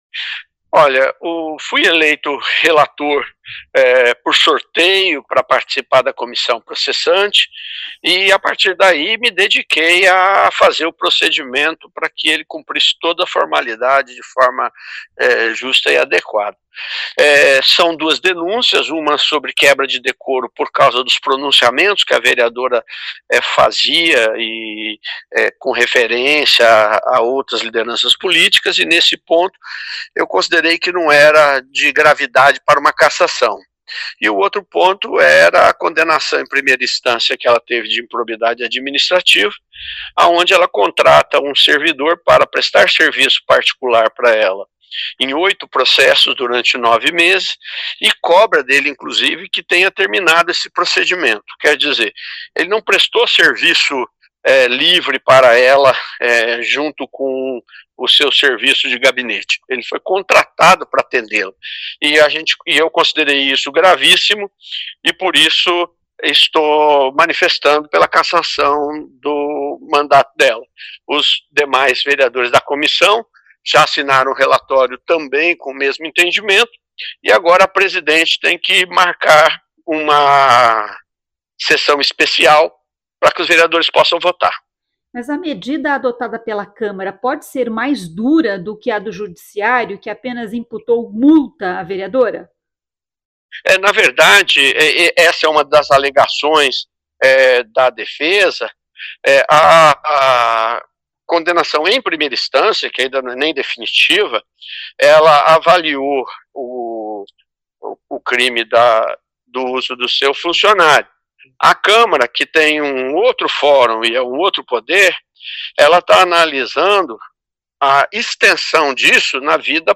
O vereador explica que a CP avaliou duas denúncias e reforçou que foi escolhido para relator por sorteio.